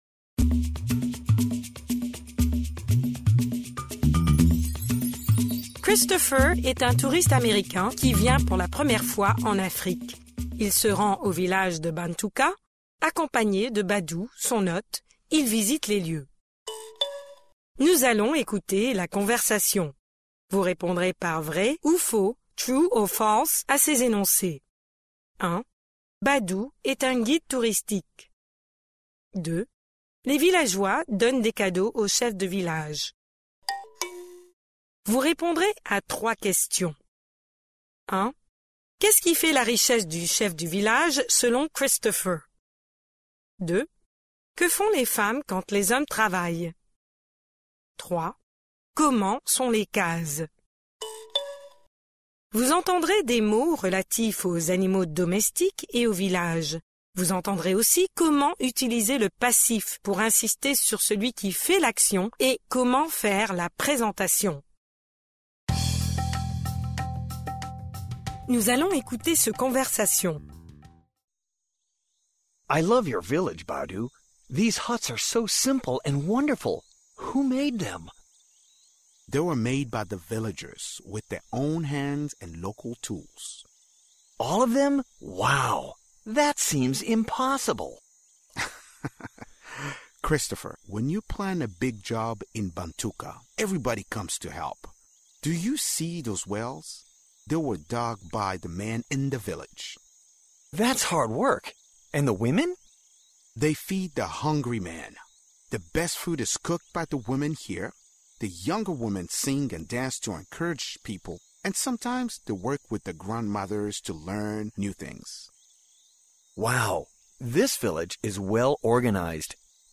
Pre-listening: Nous allons écouter la conversation.